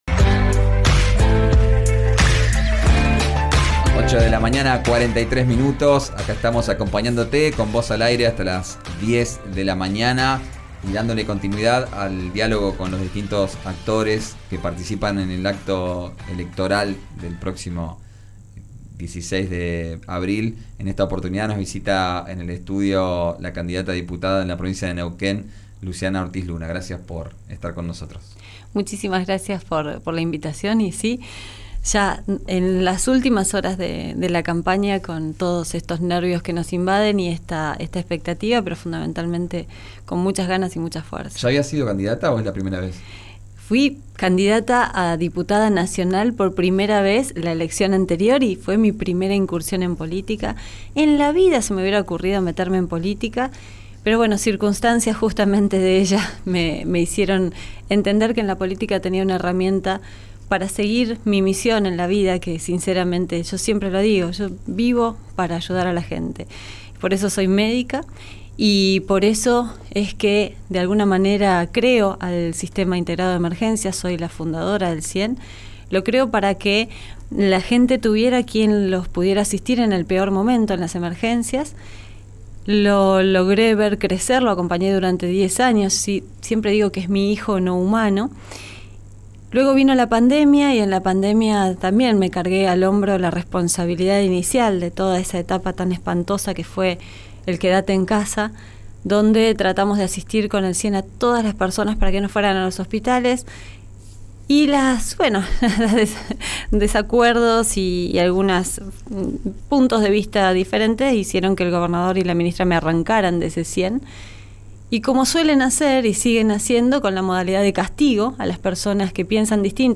En una entrevista con «Vos Al Aire», por RÍO NEGRO RADIO